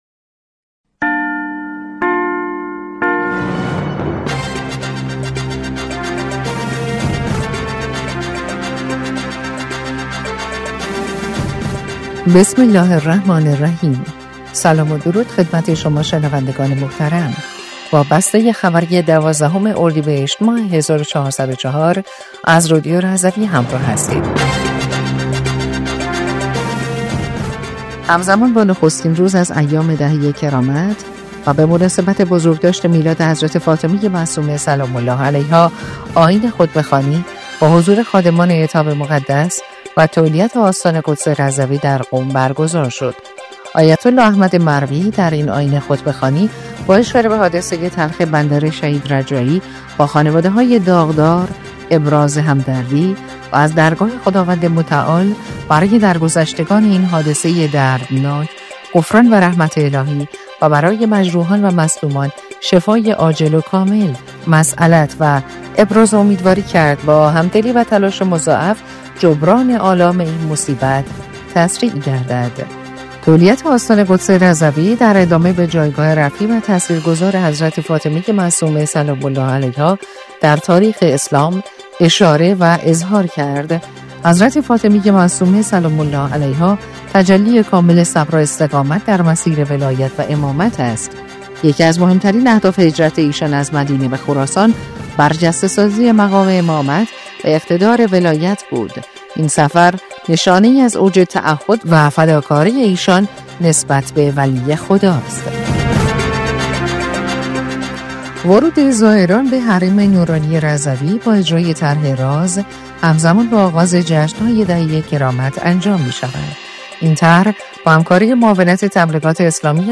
بسته خبری 12 اردیبهشت رادیو رضوی؛